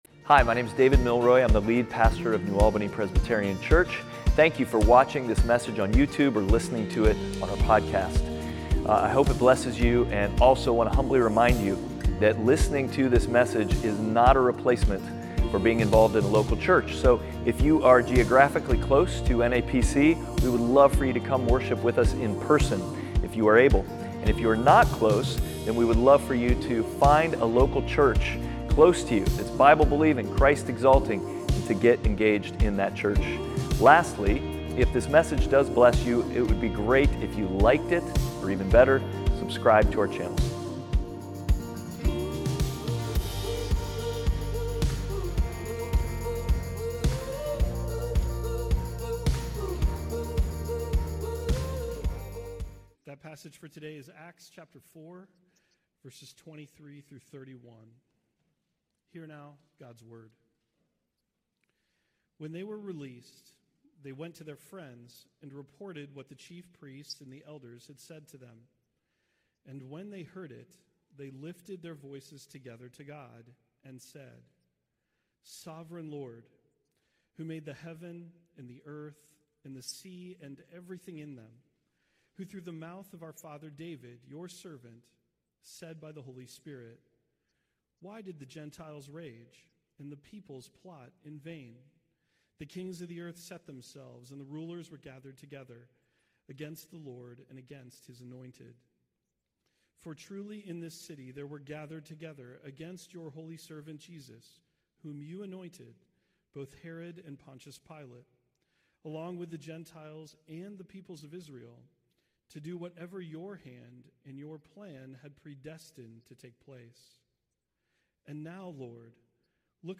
NAPC_Sermon_11.9.25.mp3